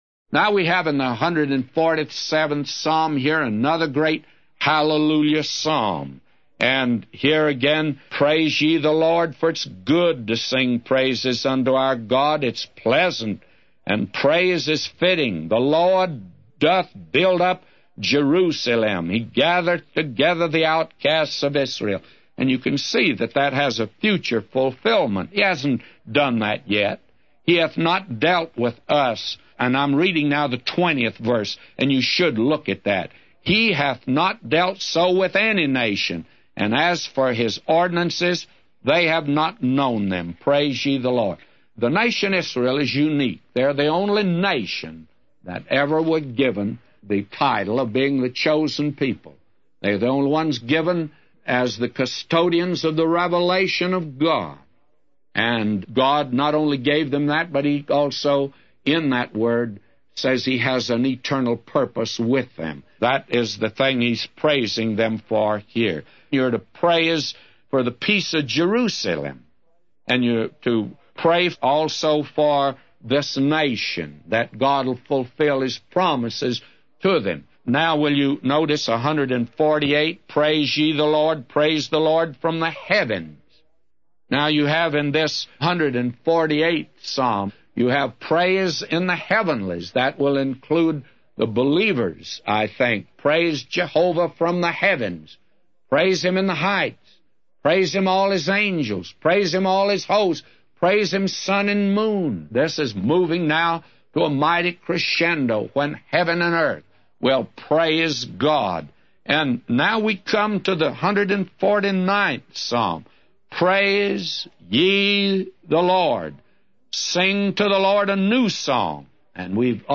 A Commentary By J Vernon MCgee For Psalms 147:1-999